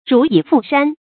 如蟻附膻 注音： ㄖㄨˊ ㄧˇ ㄈㄨˋ ㄕㄢ 讀音讀法： 意思解釋： 附：趨附；膻：羊肉的氣味。